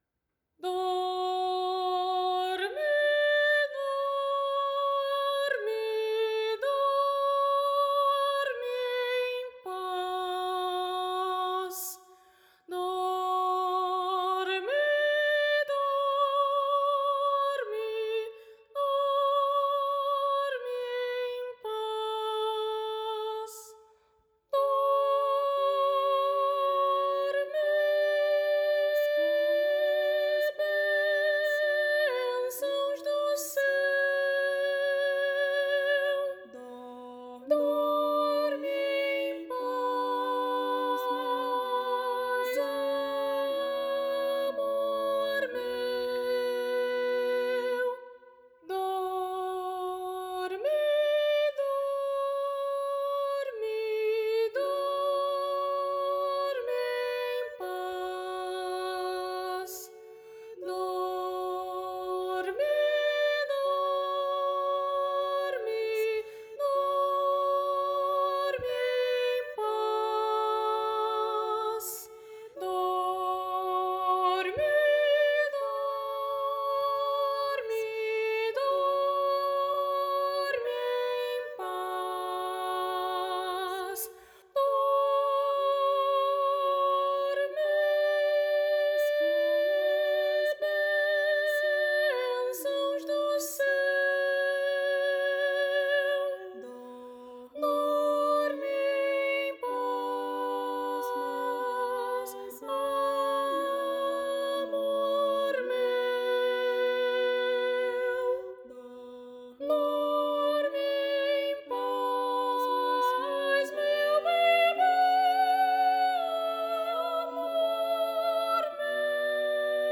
para coro infantil a três vozes
uma singela canção de ninar
Voz Guia